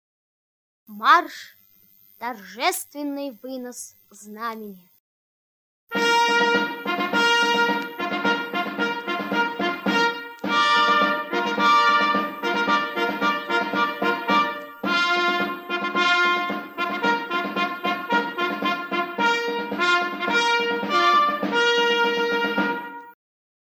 Марш - "Торжественный вынос знамени"